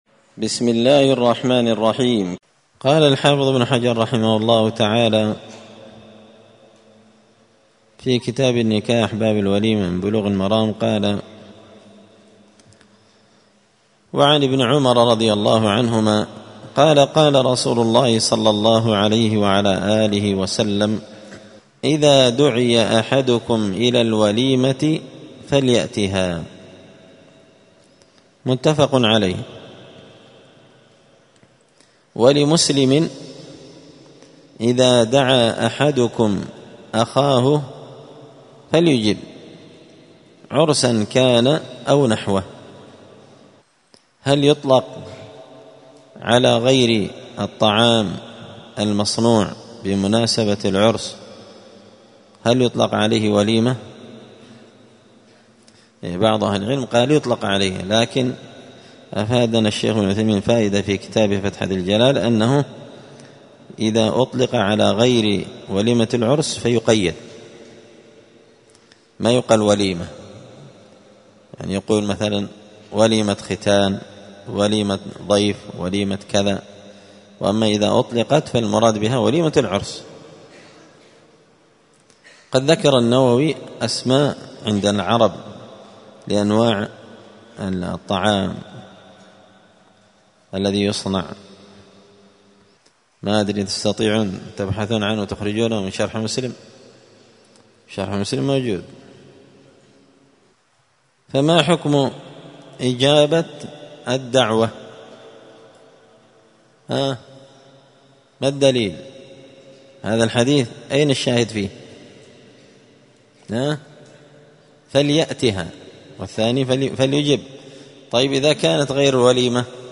الخميس 11 ربيع الثاني 1445 هــــ | 3كتاب النكاح، الدروس، سبل السلام شرح بلوغ المرام لابن الأمير الصنعاني | شارك بتعليقك | 70 المشاهدات
مسجد الفرقان_قشن_المهرة_اليمن